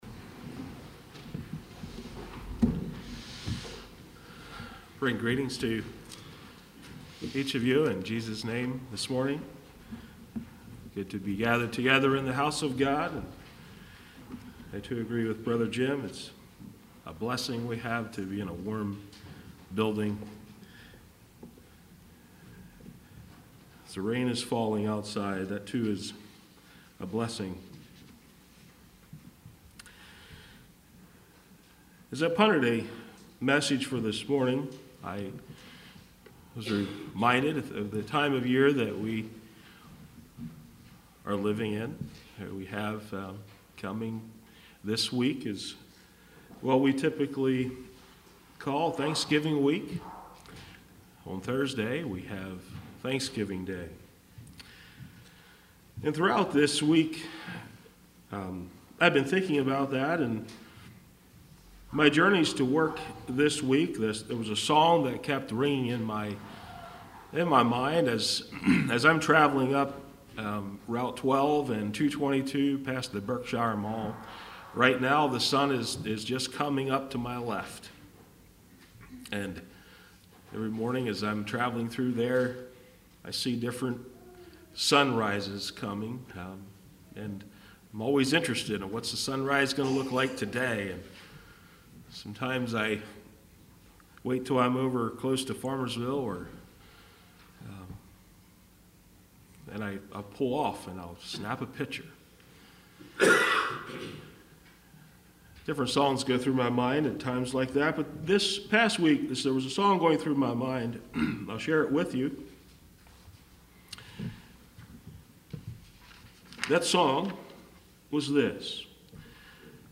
2019 Sermon ID